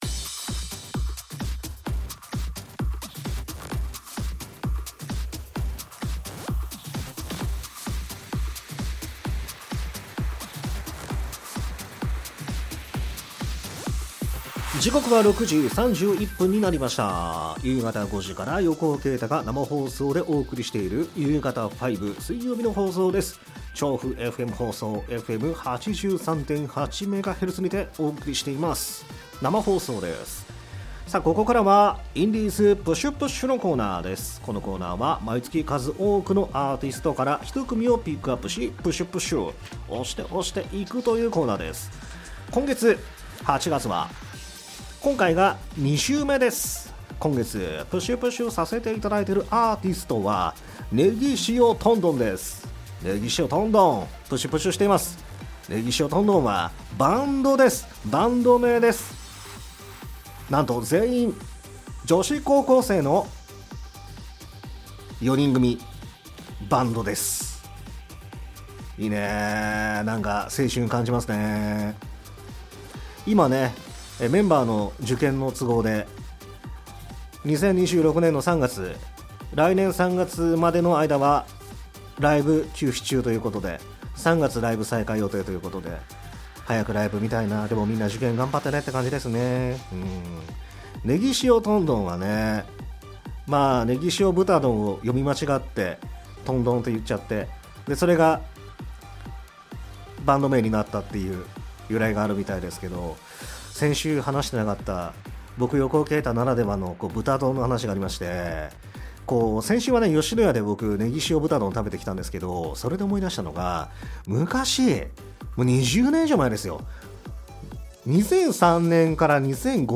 ※アーカイブでは楽曲カットしています